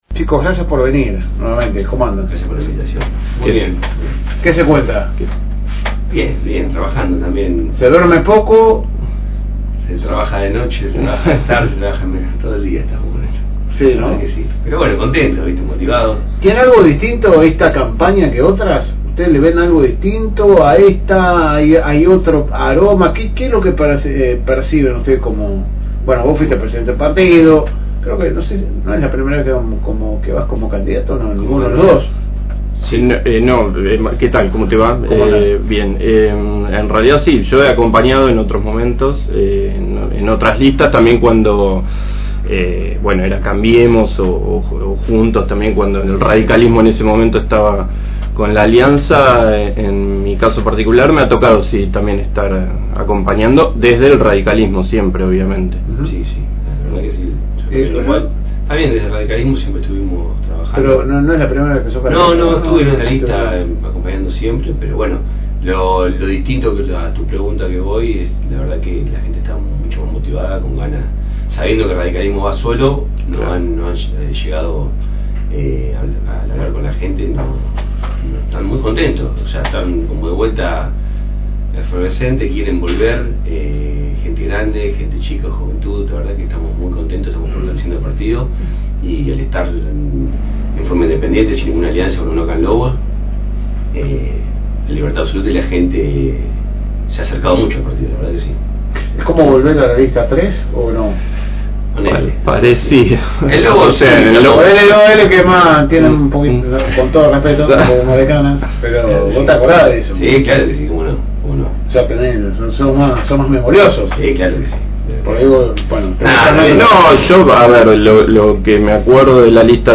En el espacio que la radio comparte con los y las candidatas para que puedan acercar sus propuestas a los y las lobenses y miradas de la ciudad, los radicales narraban como viene la campaña a dos semanas de las elecciones del domingo 7 de septiembre.